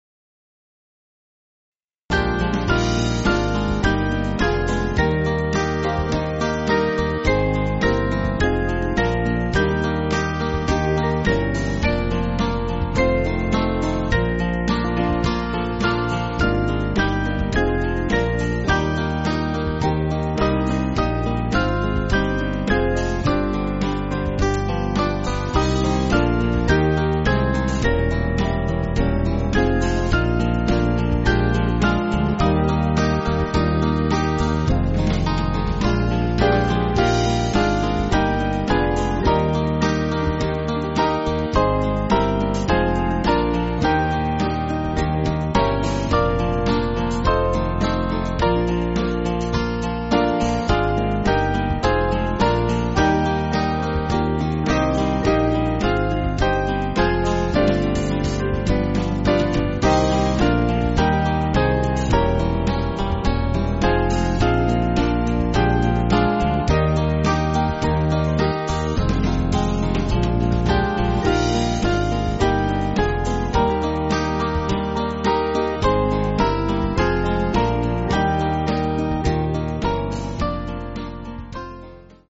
Small Band
(CM)   5/Eb